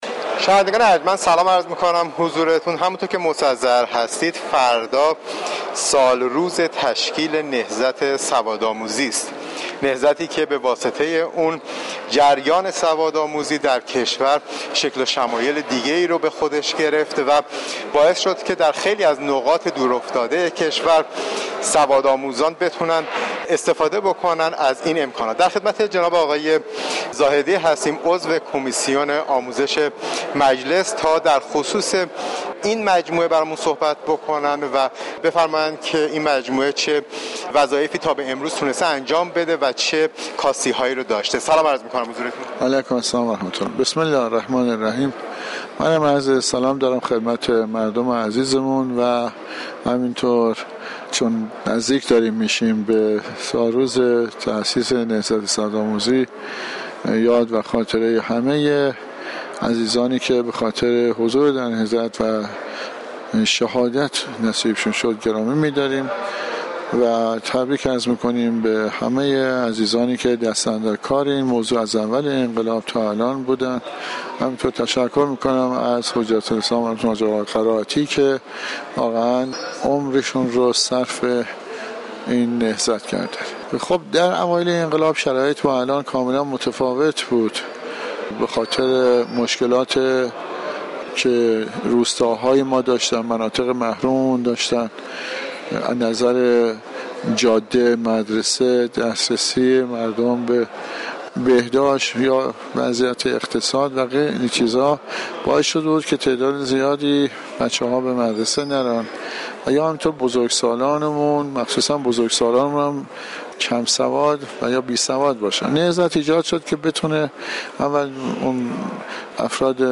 گفت و گوی اختصاصی